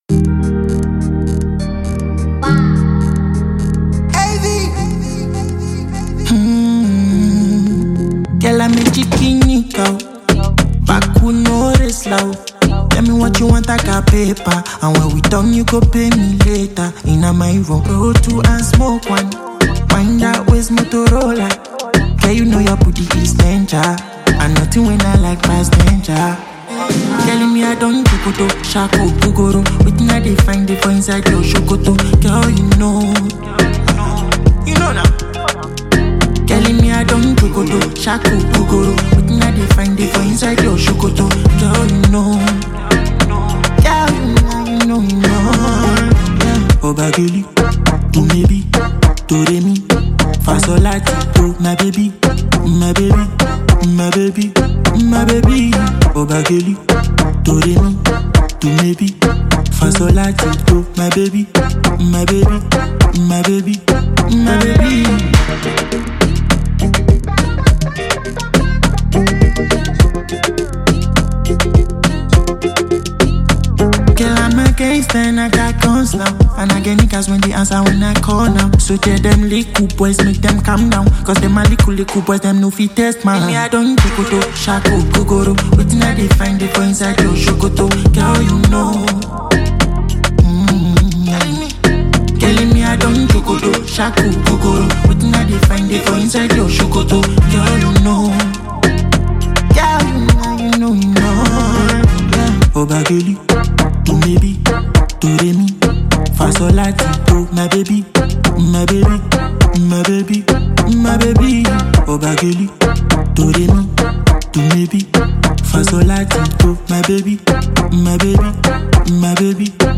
a contagious record that would keep you on the dancefloor